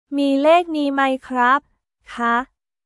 ミー・ロッタリー・マイ・クラップ／カー？